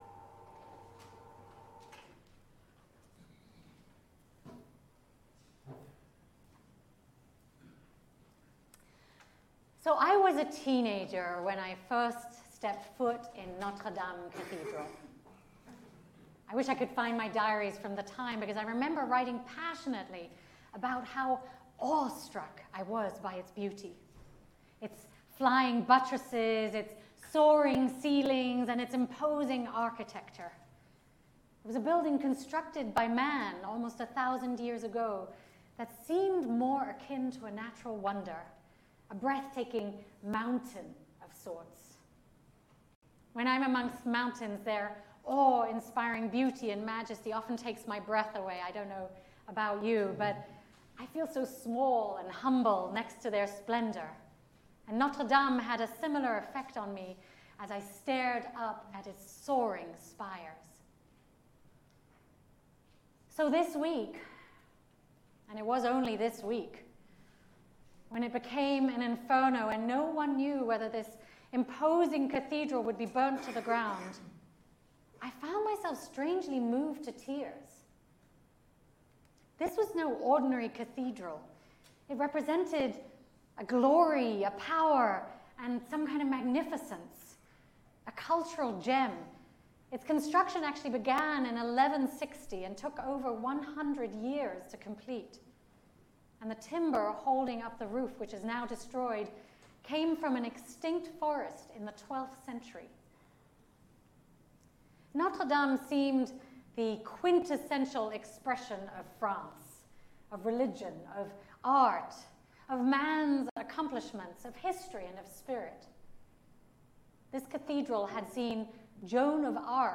On this Easter Sunday, we will celebrate our traditional UU Flower Communion.